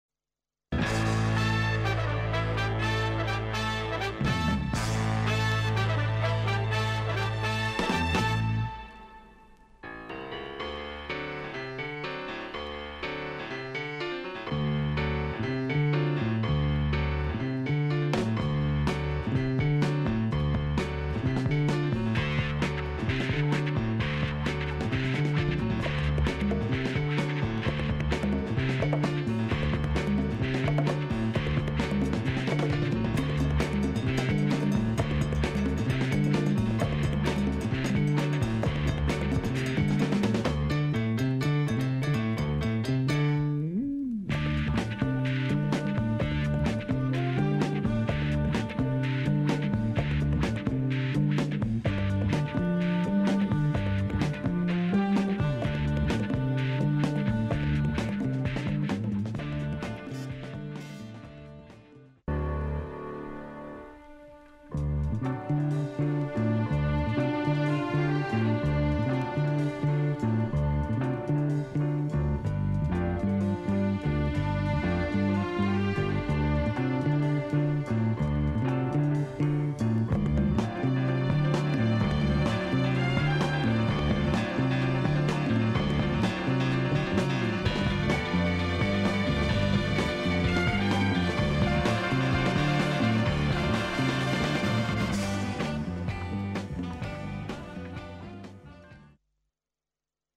classic pop groove dancer